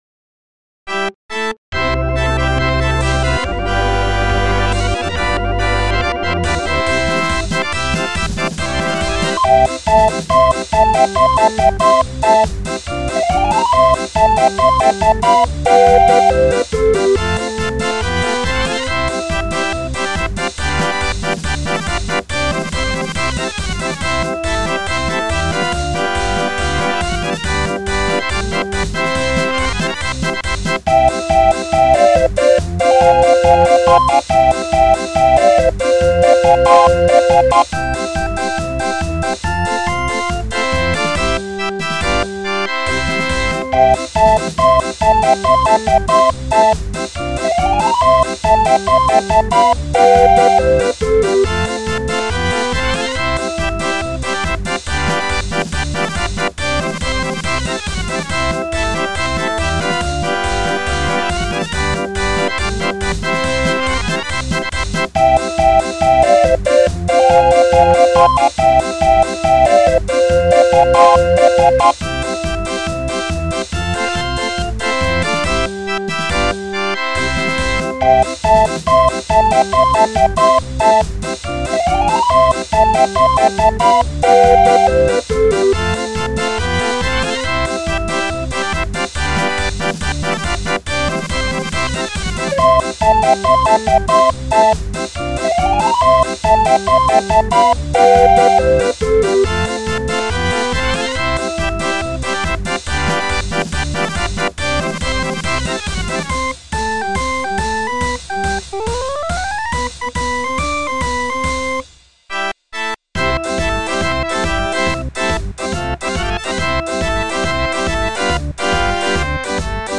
Music rolls, music books and accessoires for barrel organs.